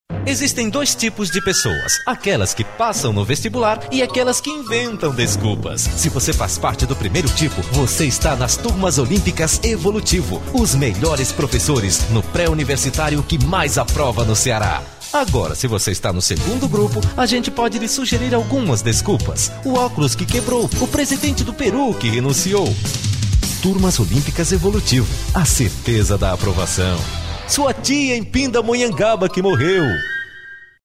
Achei num MD alguns spots de rádio que fiz quando morava no Ceará.